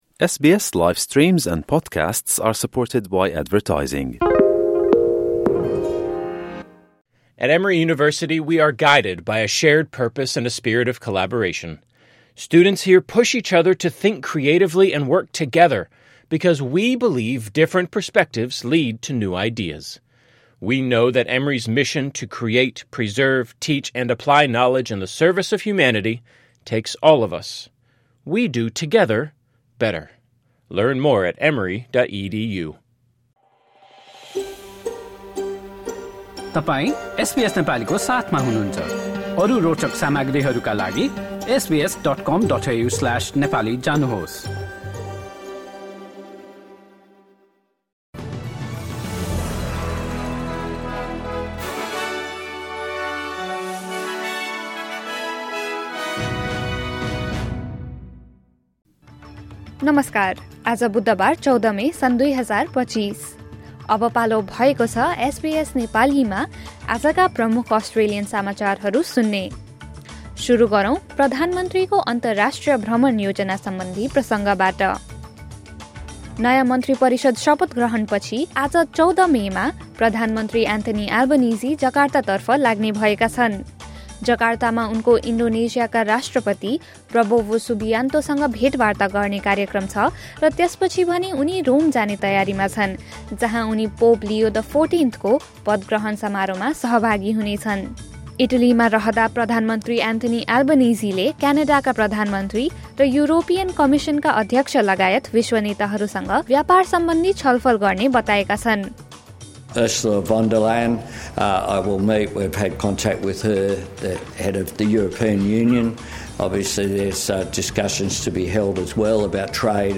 एसबीएस नेपाली प्रमुख अस्ट्रेलियन समाचार: बुधवार, १४ मे २०२५